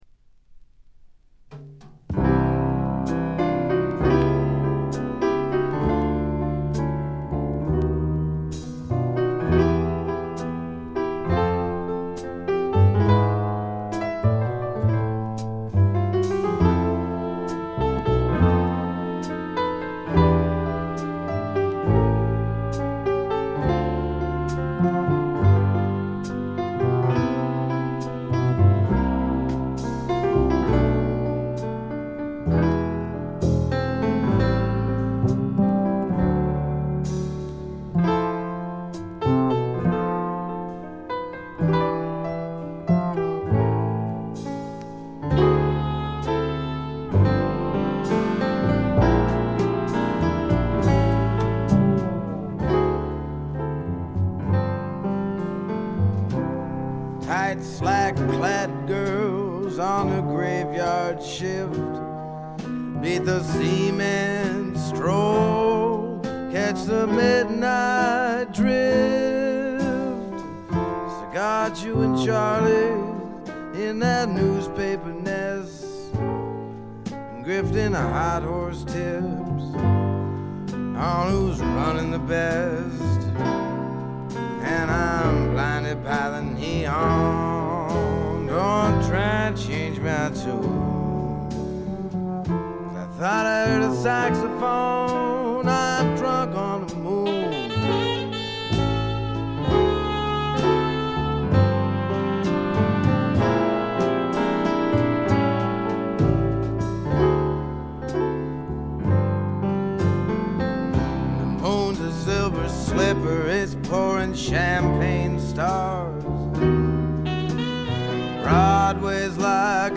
軽微なチリプチ程度。
メランコリックでぞっとするほど美しい、初期の名作中の名作です。
試聴曲は現品からの取り込み音源です。